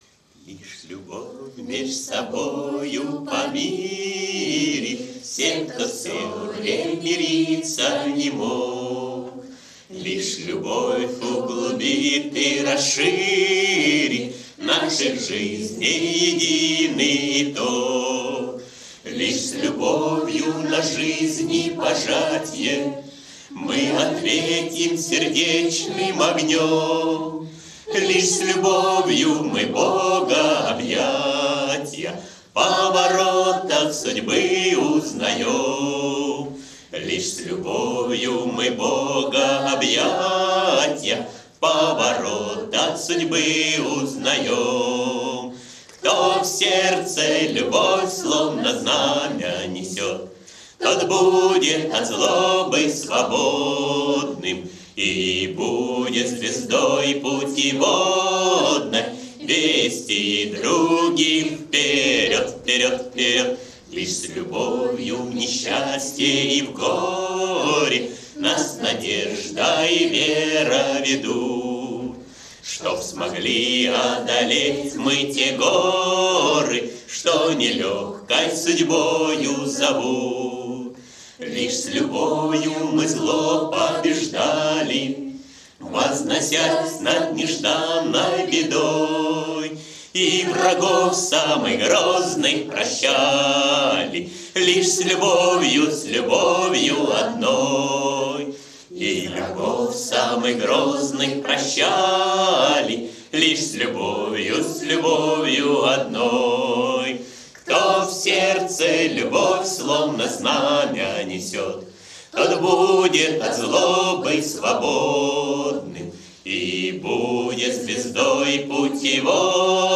кавер-версия
Костровые